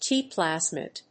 Tiプラスミド; ティーアイプラスミド